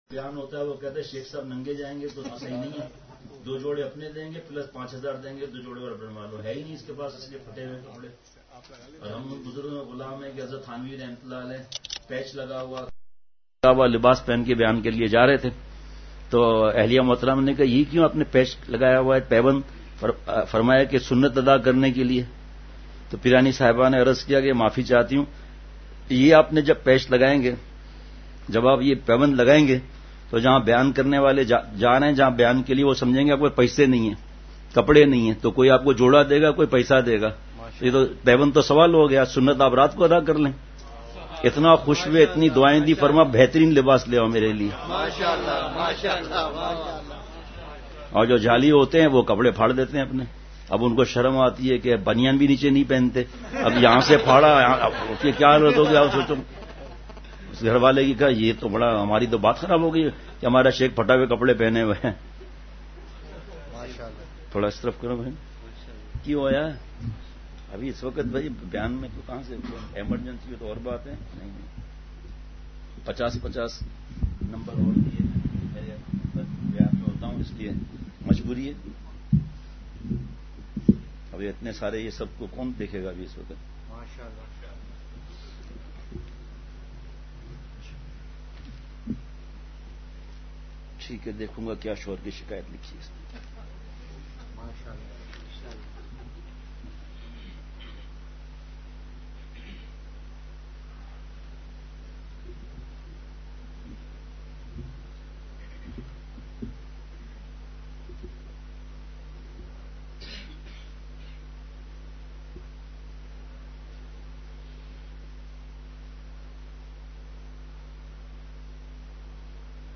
نعتیہ اشعار